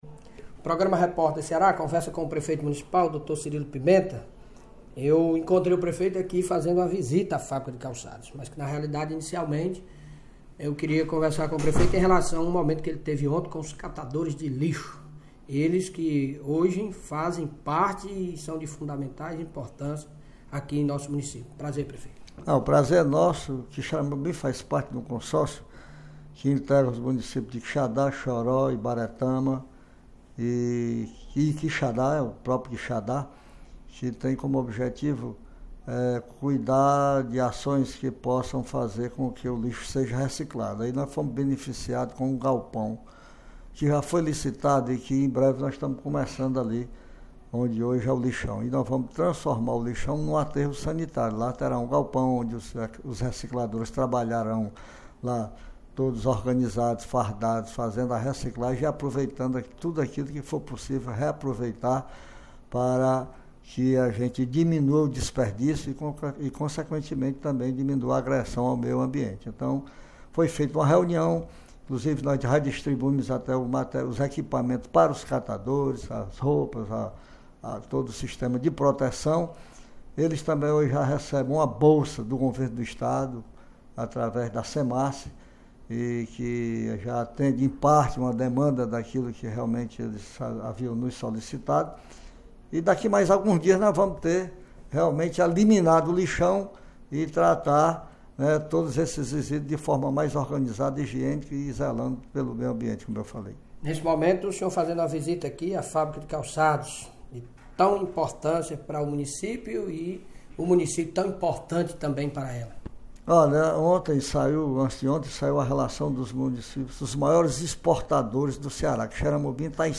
Em entrevista à Rádio Campo Maior AM 840, emissora que integra o Sistema Maior de Comunicação, o prefeito de Quixeramobim, Cirilo Pimenta (PSB), informou nessa quinta-feira, 11, que a cidade foi beneficiada com um galpão que será construído no espaço que hoje está localizado o lixão. O espaço será transformado em um aterro sanitário, onde os recicladores deverão trabalhar na transformação dos resíduos em reciclagem.